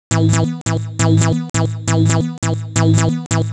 Em (E Minor - 9A) Free sound effects and audio clips
• techno synth sequence 136 5.wav
techno_synth_sequence_136_5_2EH.wav